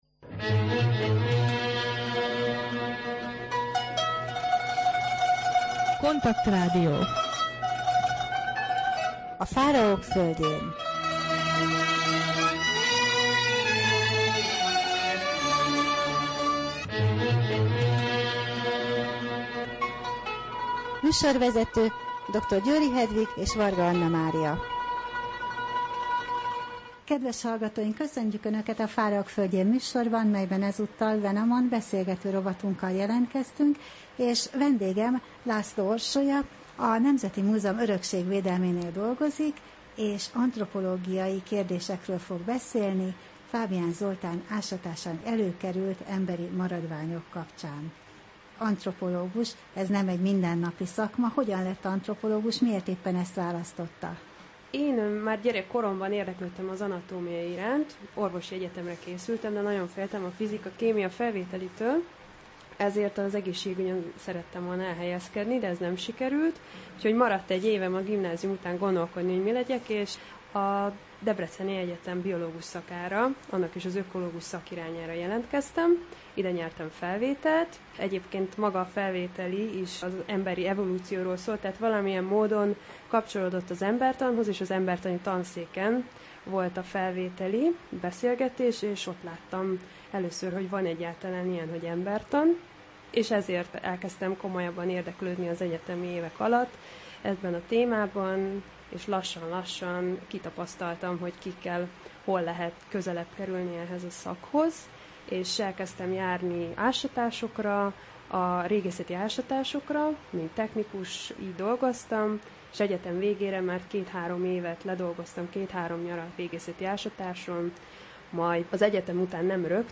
Rádió: Fáraók földjén Adás dátuma: 2014, August 15 Wenamon beszélgető rovat / KONTAKT Rádió (87,6 MHz) 2014. augusztus 15.